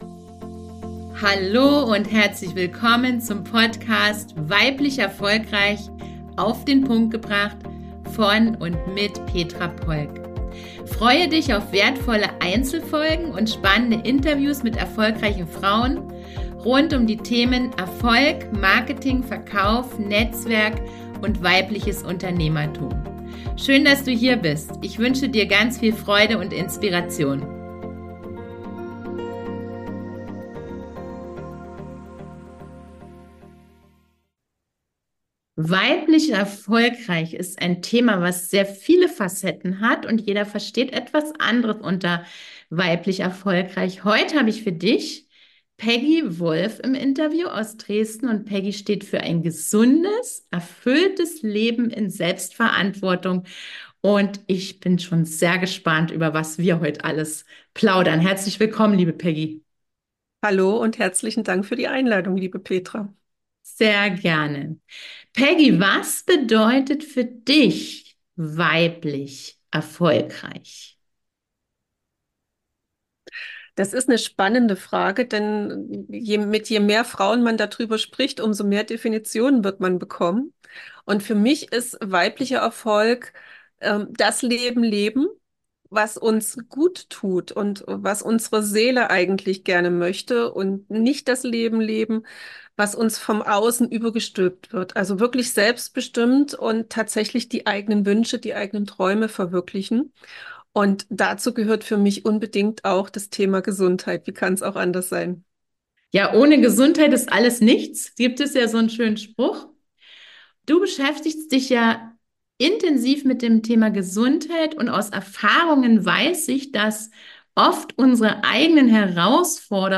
Gesundheit als Grundlage für ein erfolgreiches Business: Interview